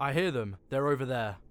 Voice Lines / Barklines Combat VA
Marcel I hear them,Theyre here.wav